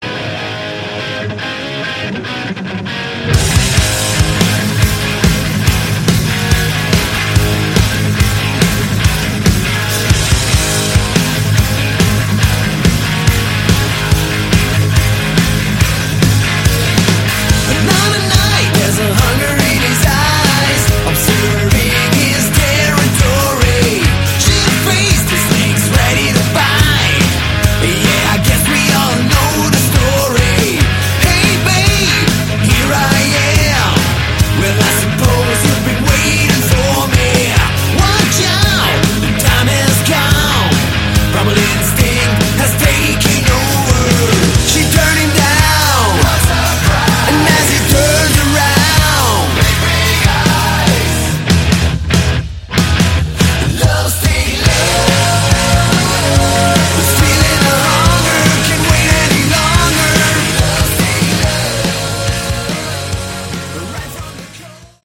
Category: Hard Rock
vocals, guitar
lead guitar